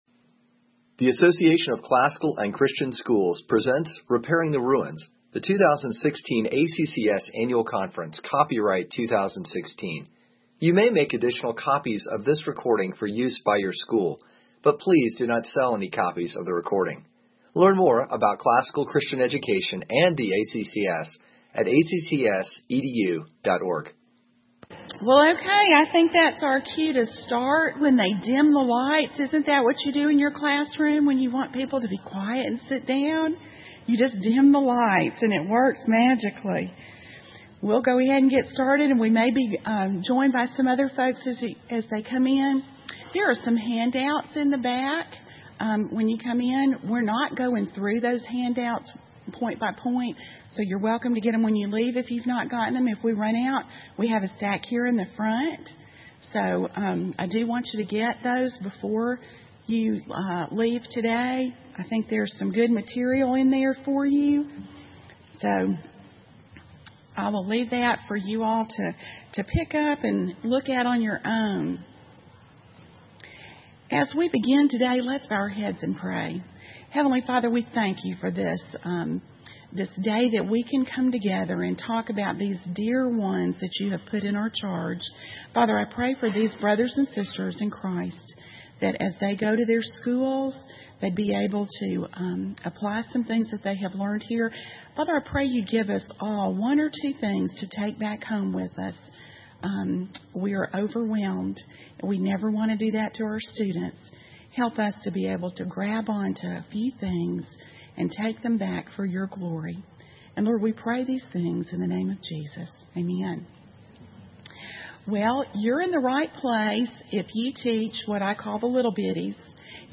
2016 Workshop Talk | 1:04:48 | K-6, Literature